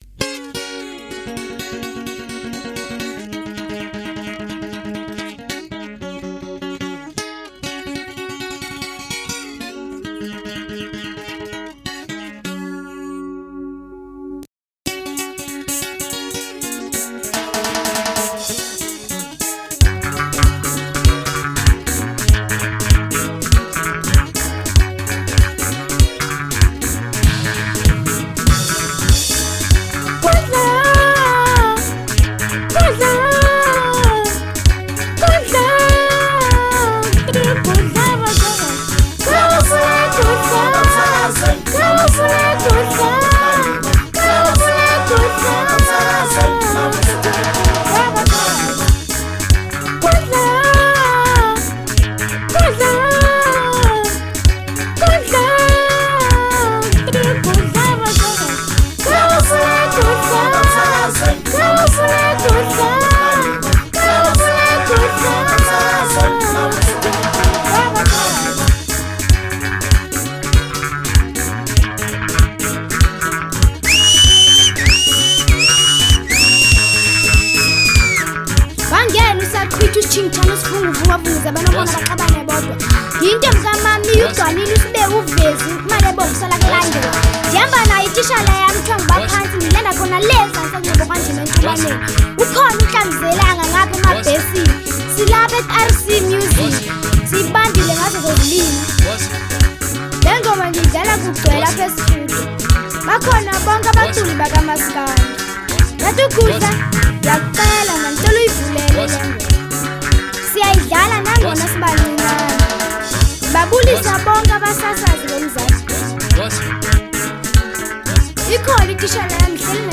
04:42 Genre : Maskandi Size